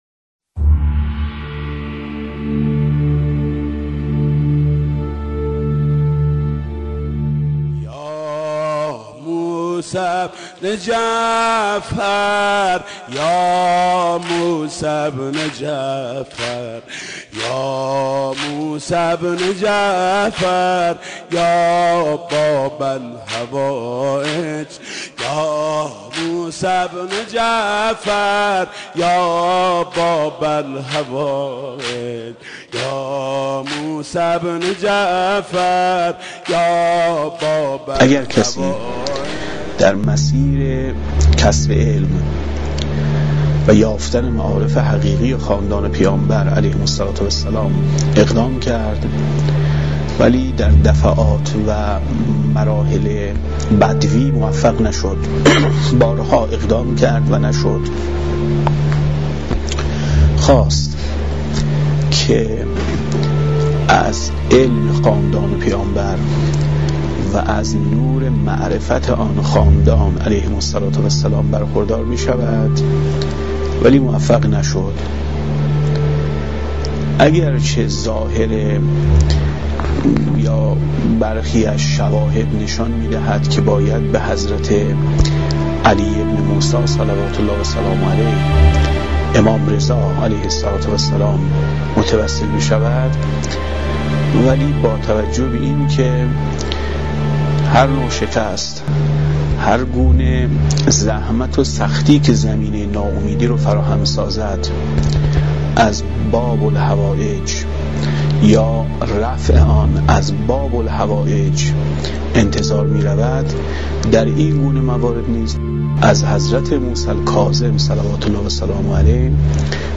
دسته: آواهنگ